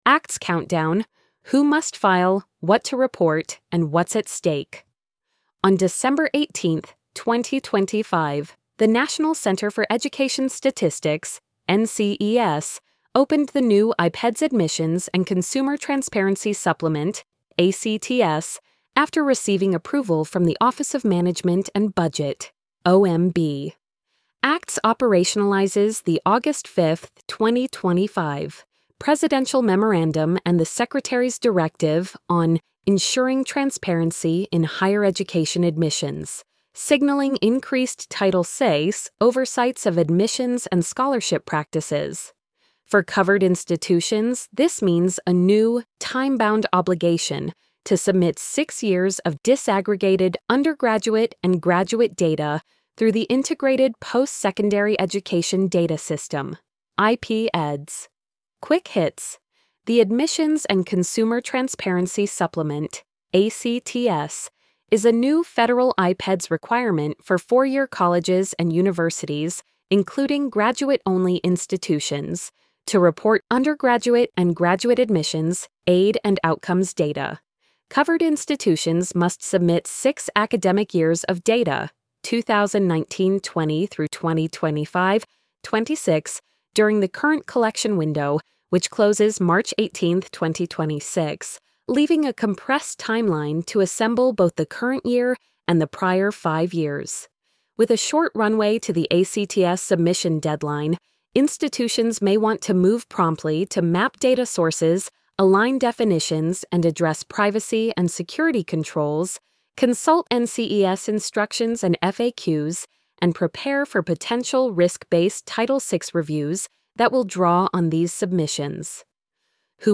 acts-countdown-who-must-file-what-to-report-and-whats-at-stake-tts.mp3